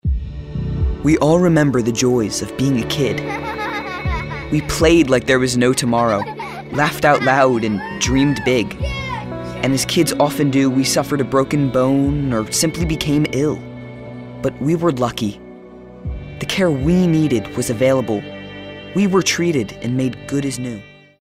anti-announcer, caring, compelling, genuine, real, teenager, thoughtful, warm, young